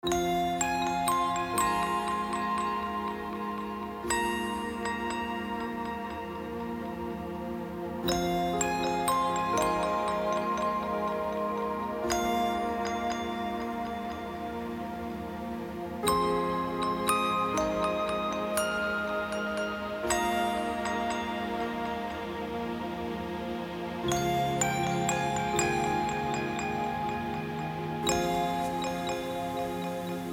موسیقی بی کلام ریتمیک آرام